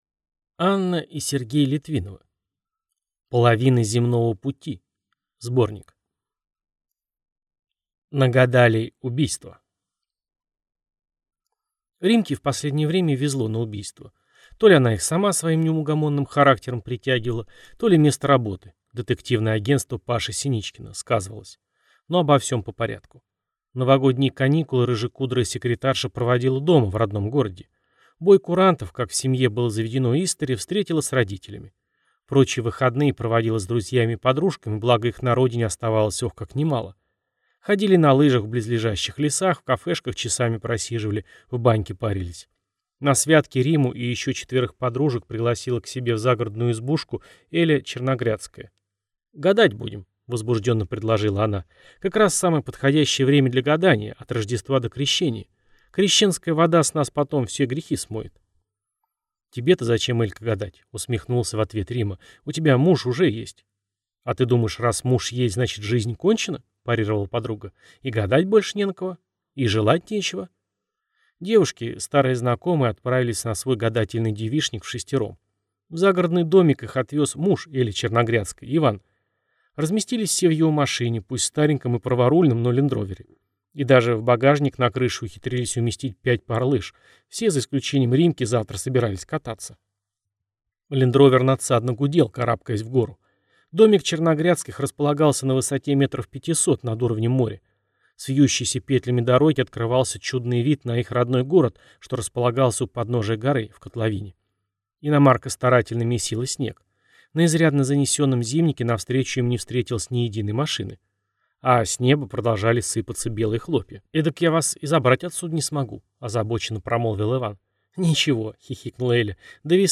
Аудиокнига Половина земного пути (сборник) | Библиотека аудиокниг